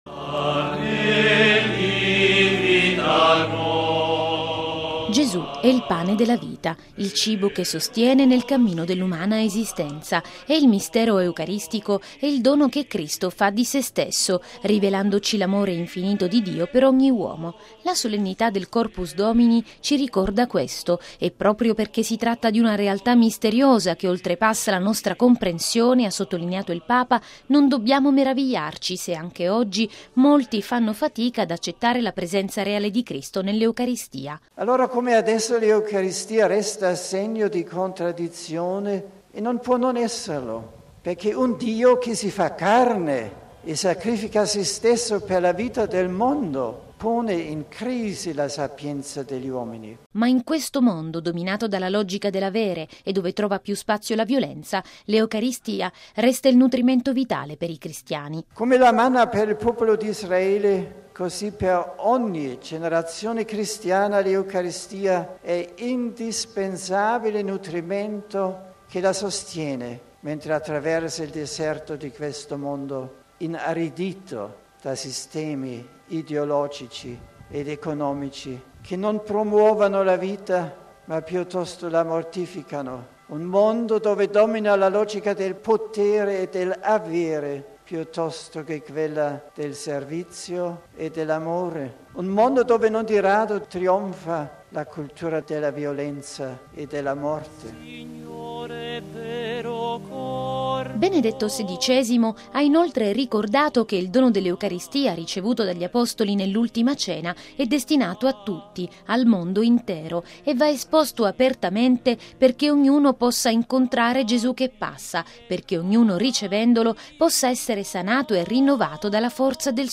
◊   L’Eucaristia è per ogni generazione cristiana l’indispensabile nutrimento nel deserto di questo mondo inaridito da sistemi ideologici ed economici che mortificano la vita e dove domina la logica del potere. Lo ha detto il Papa, ieri pomeriggio, durante la Messa per l’odierna solennità del Santissimo Corpo e Sangue di Gesù. Benedetto XVI ha presieduto la tradizionale celebrazione eucaristica sul sagrato della Basilica di San Giovanni in Laterano ed ha poi raggiunto in processione la Basilica di Santa Maria Maggiore.